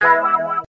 neptunesambient3.wav